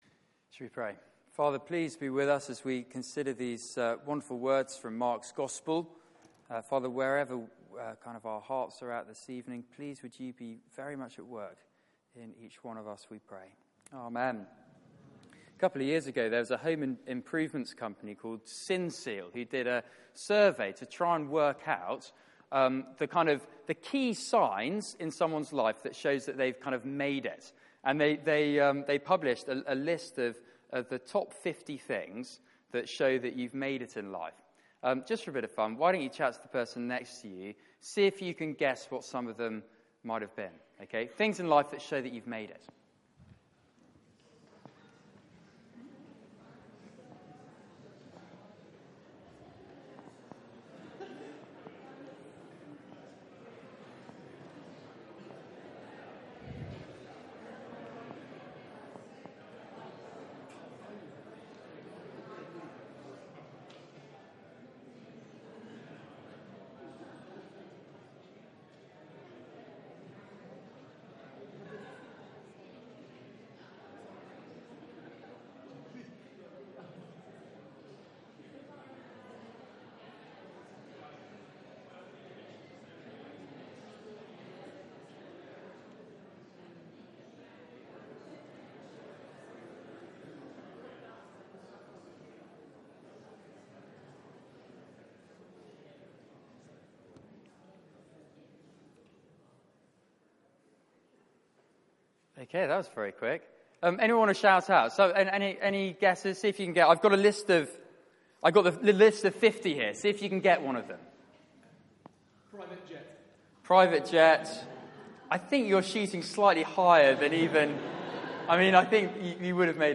Following Jesus Passage: Mark 10:32-52 Service Type: Weekly Service at 4pm Bible Text